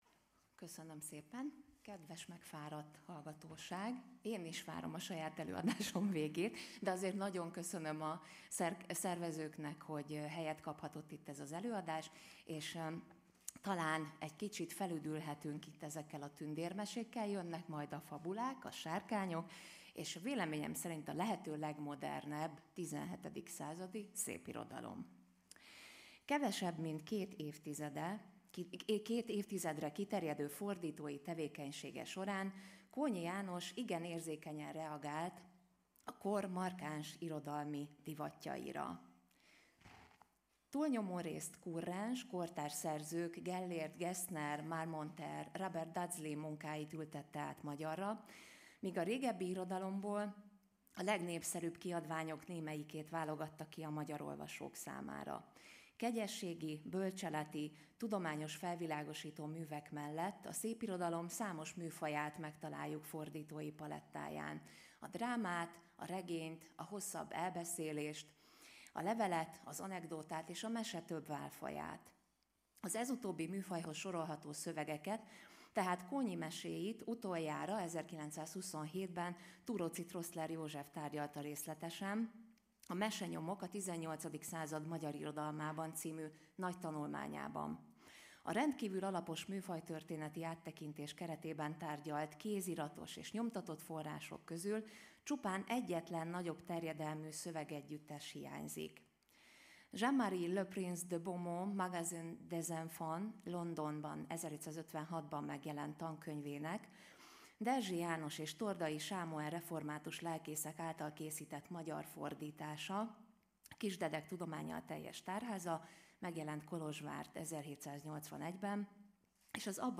lecturer